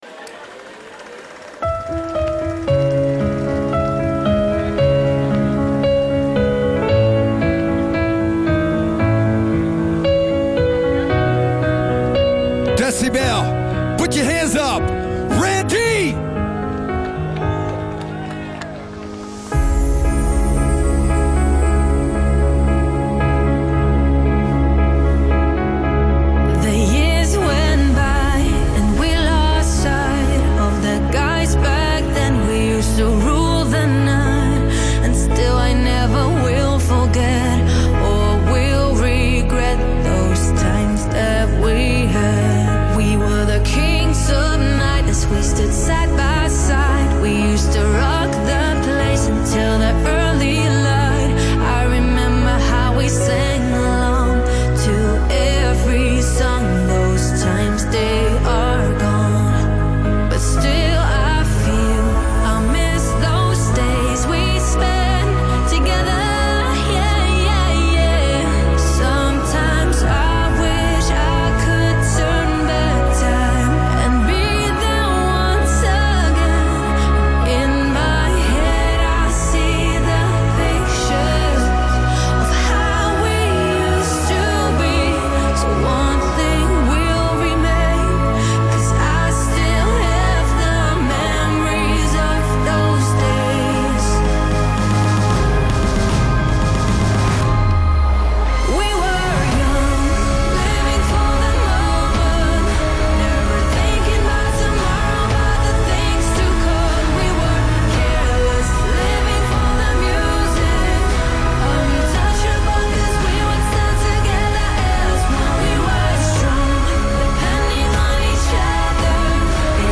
Genre: Hardstyle